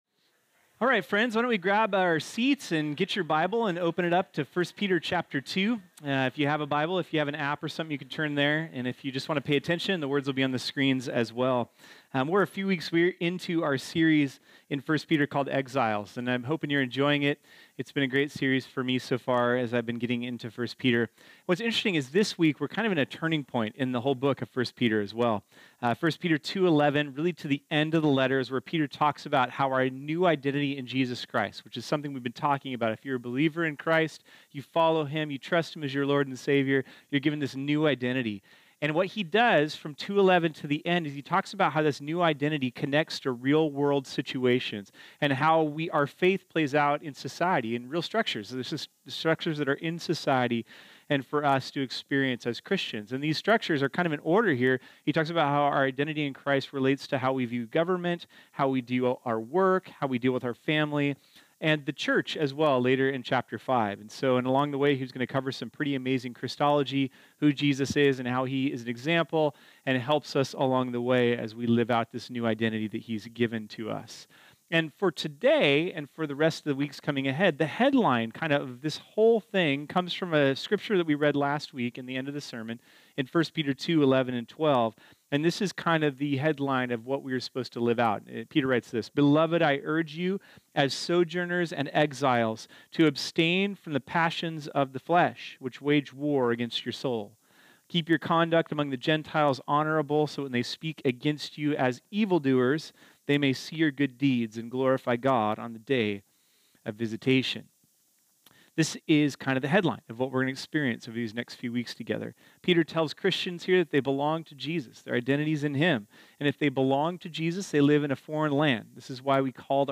This sermon was originally preached on Sunday, March 11, 2018.